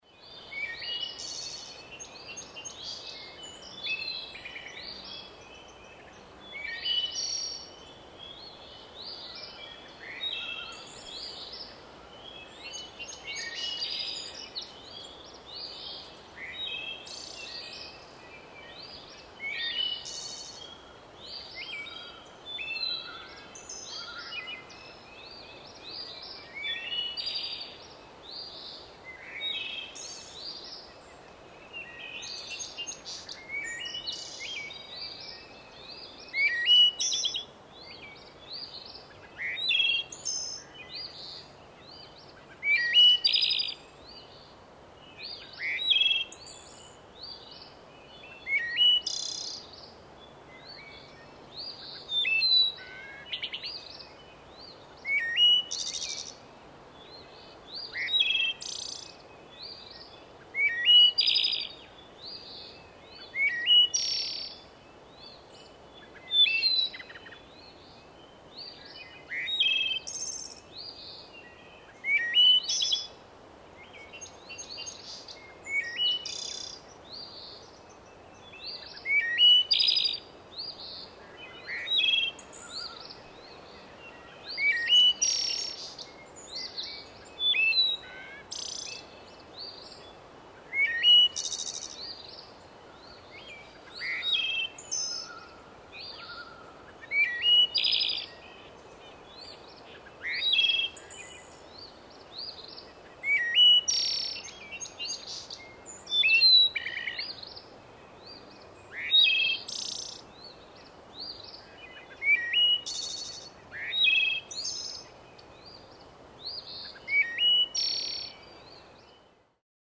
Звуки леса
Лесные звуки дня